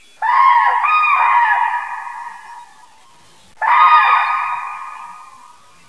Tranans s�ng
trana.wav